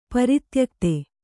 ♪ pari tyakte